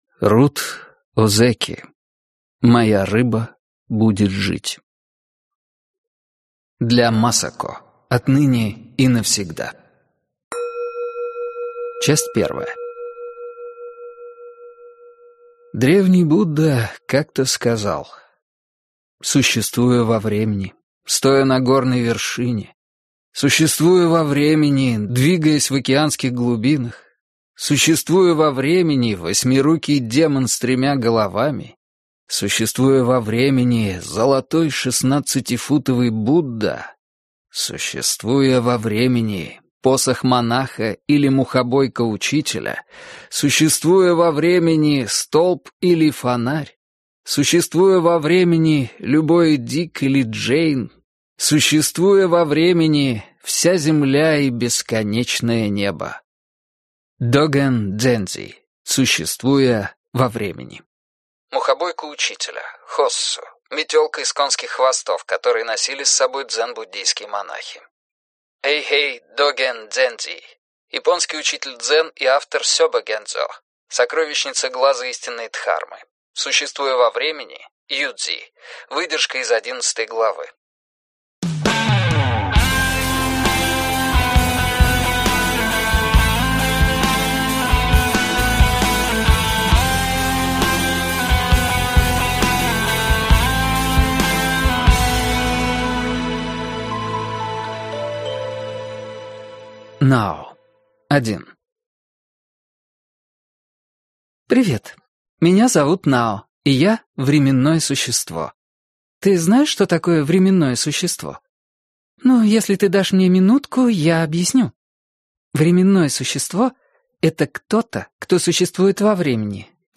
Аудиокнига Моя рыба будет жить - купить, скачать и слушать онлайн | КнигоПоиск